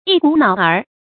一股腦兒 注音： ㄧ ㄍㄨˇ ㄣㄠˇ ㄦ 讀音讀法： 意思解釋： 通通。